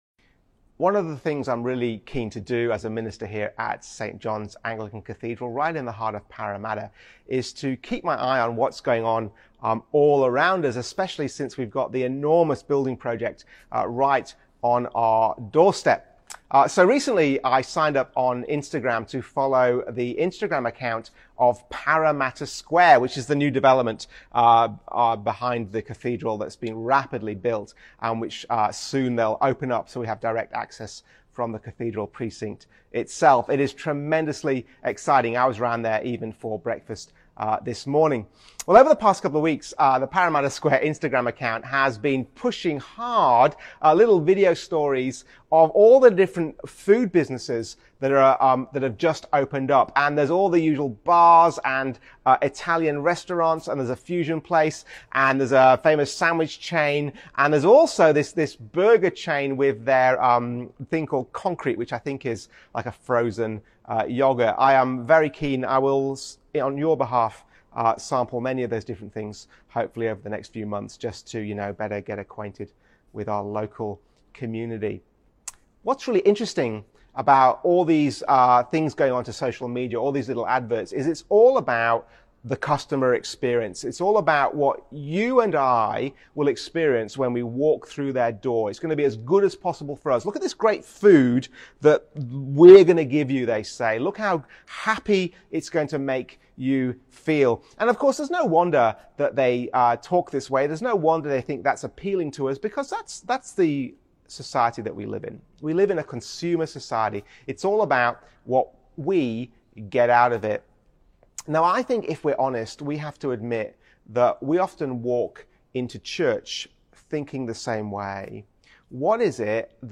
Sermons | St Johns Anglican Cathedral Parramatta